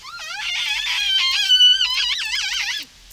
I briefly mentioned our encounter with K21, Cappuccino, making the S10 call by our hydrophone before, but here I will give you a few more details.